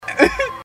Laugh 3